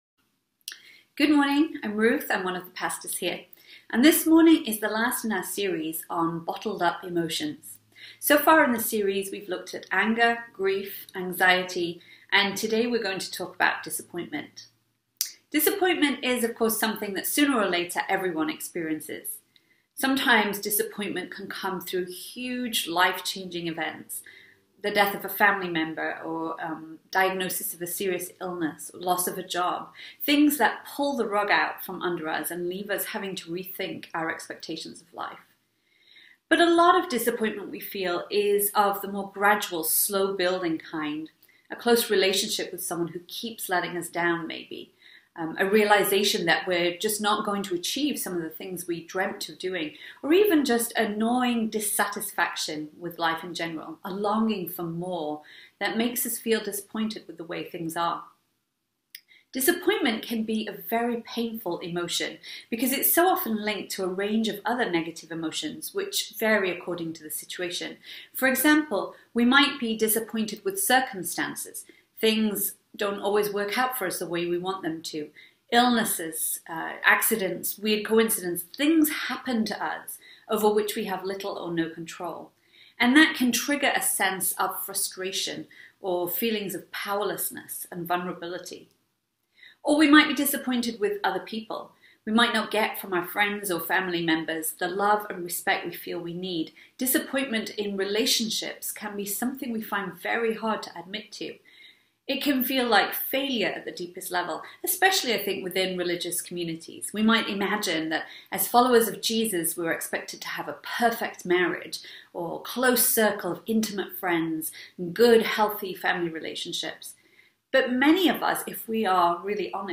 Message: “Owning Our Emotions”